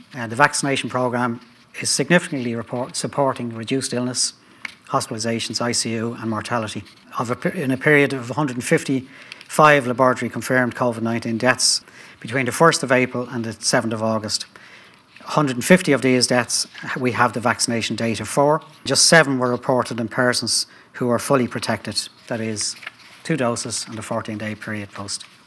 HSE CEO Paul Reid says the rollout is now in its “final leg”, and says the vaccines are proven to offer strong protection from Covid: